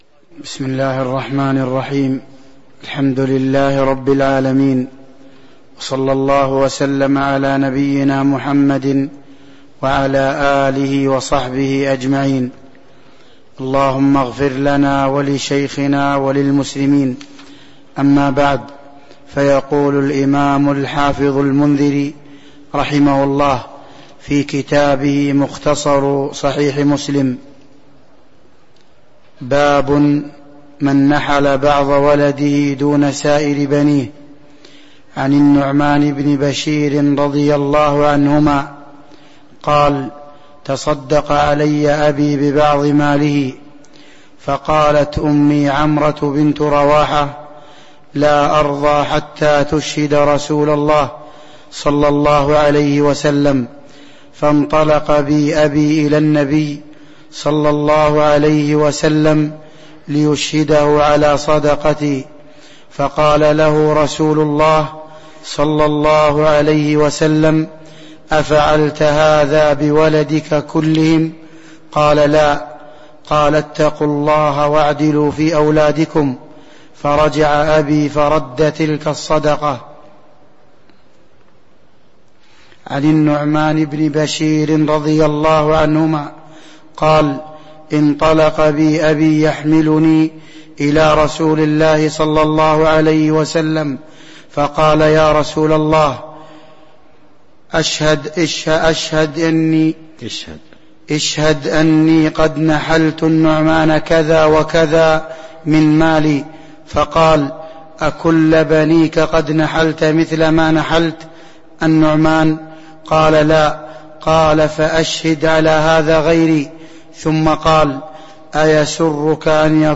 تاريخ النشر ٢١ صفر ١٤٤٣ هـ المكان: المسجد النبوي الشيخ: فضيلة الشيخ عبد الرزاق بن عبد المحسن البدر فضيلة الشيخ عبد الرزاق بن عبد المحسن البدر باب من نحل بعض ولده دون سائر بنيه (03)